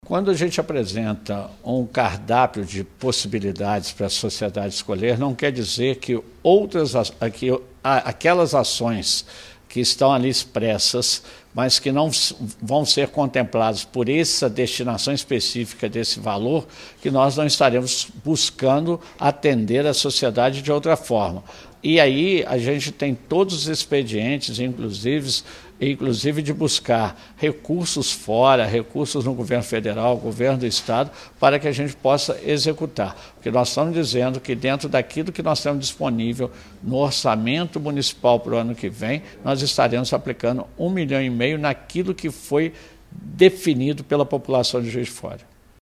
O prefeito Antônio Almas (PSDB) explica que esse investimento não vai interferir em outras prioridades do executivo.
prefeito Antonio Almas